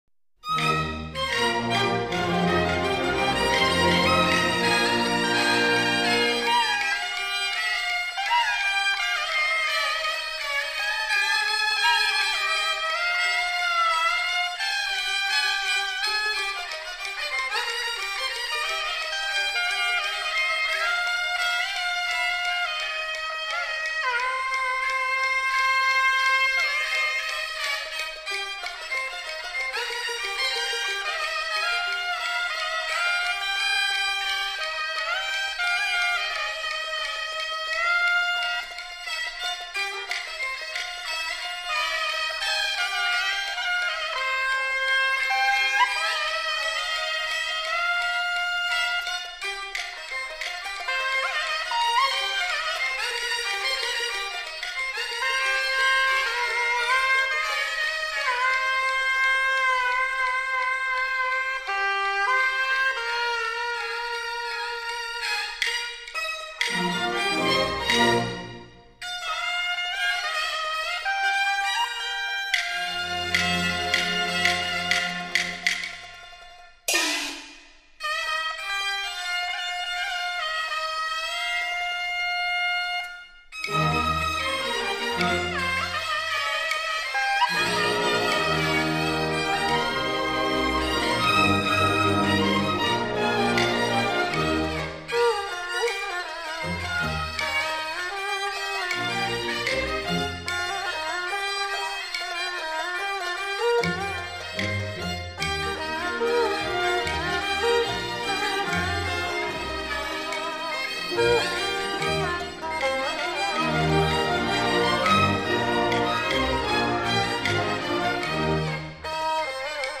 京劇交響組曲
音地點：中國·上海
嗩吶 二胡 笛子